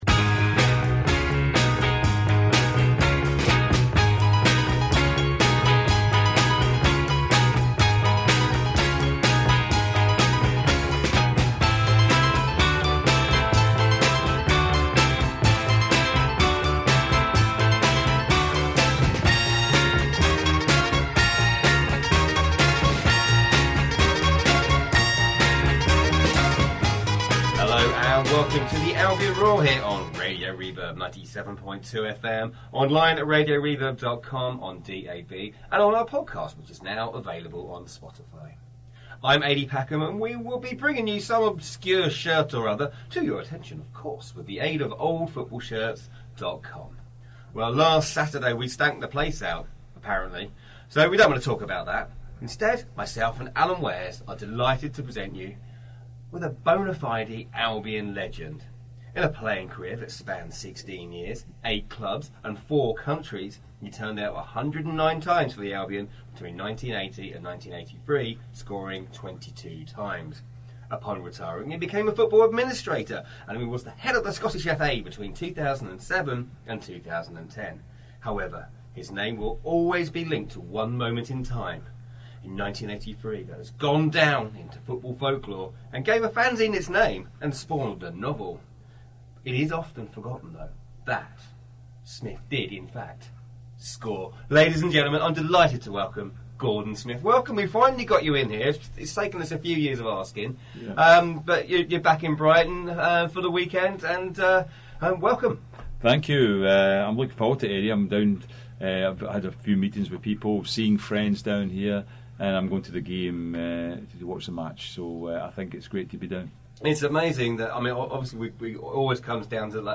in our makeshift studio in Worthing